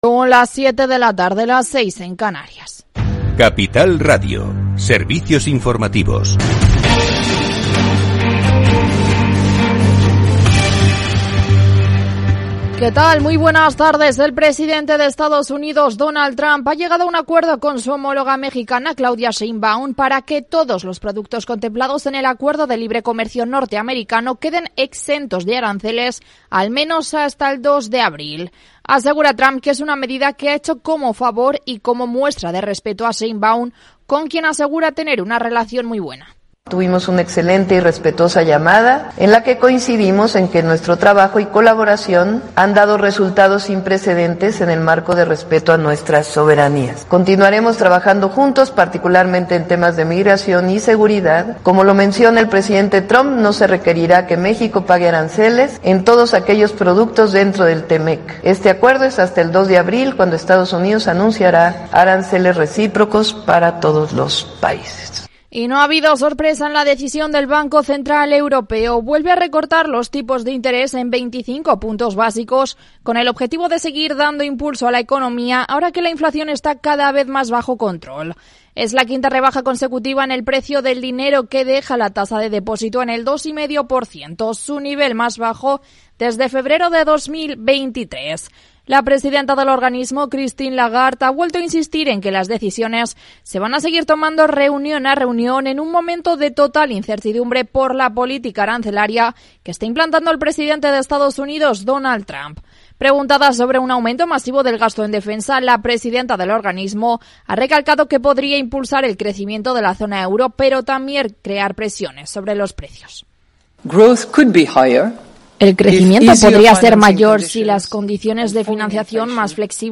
Programa diario dedicado a las pymes, profesionales, autónomos y emprendedores. Hablamos de Big Data y de crowdfunding, de management y coaching, de exportar e importar, de pedir créditos a los bancos y de empresas fintech. Los especialistas comparten sus experiencias, sus casos de éxito y sus fracasos. Todo en un tono propio de un Afterwork, en el que podrás hacer un poco de networking y hacer negocios más allá de la oficina.